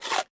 eat3.ogg